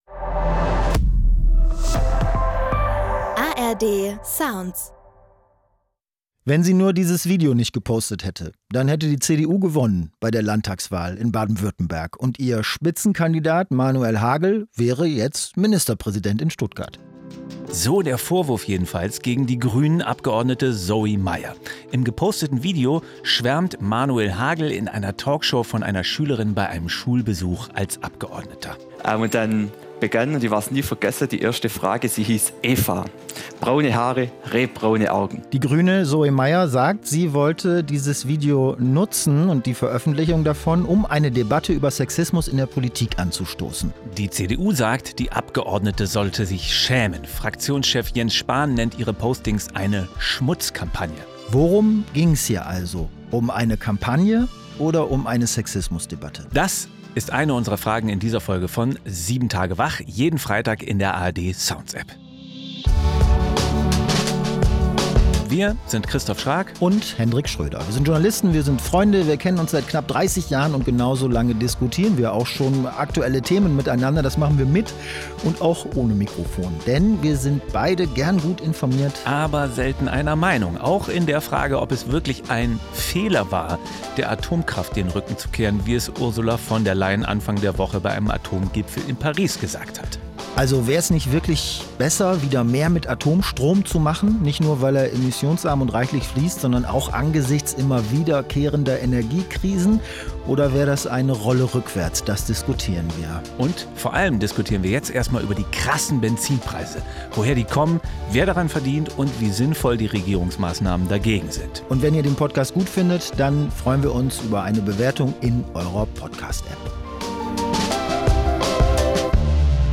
Was genau war da diese Woche los? Zwei Freunde, zwei Meinungen, ein News-Podcast: